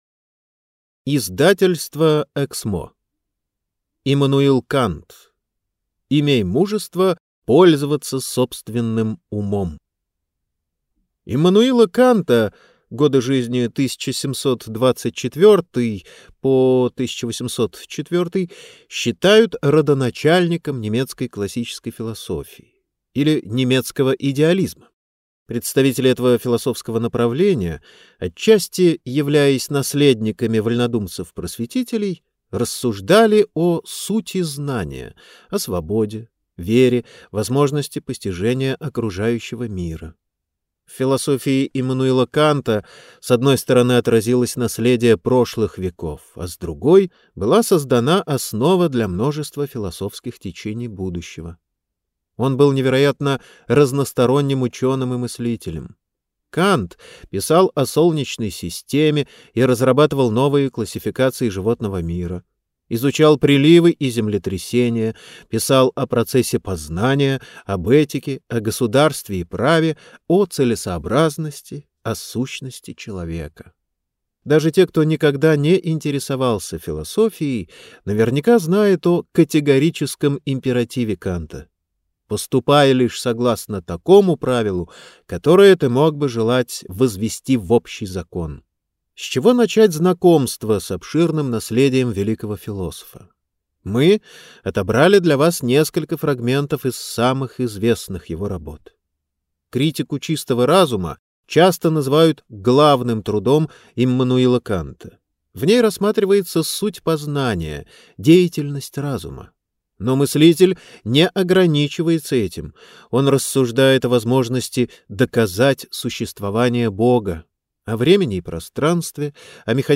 Аудиокнига Иммануил Кант.